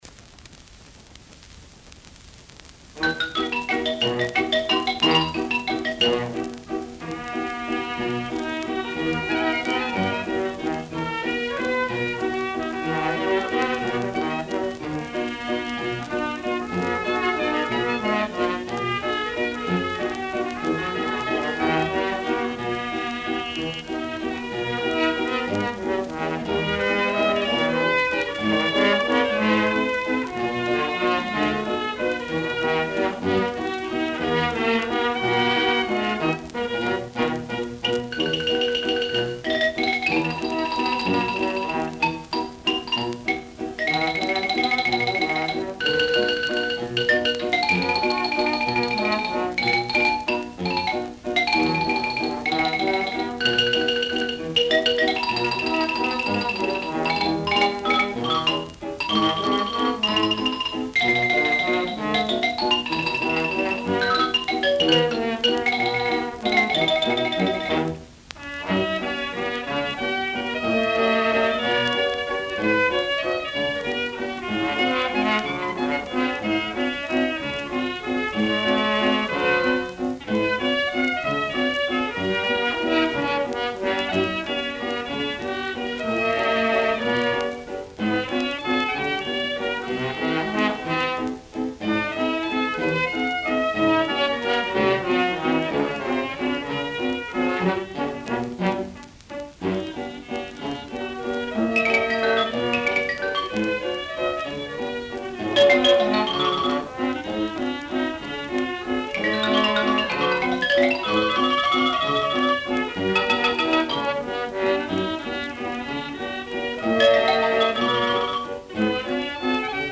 Ксилофон эдорово звучит!